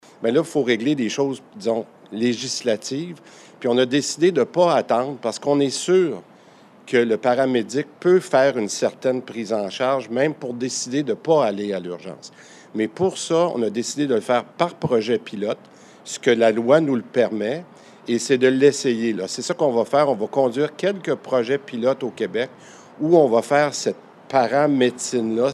Mais parce qu’il y a des limites légales à faire de la paramédecine, cette nouvelle façon de faire se fera d’abord par le biais de projets pilotes comme l’explique Christian Dubé.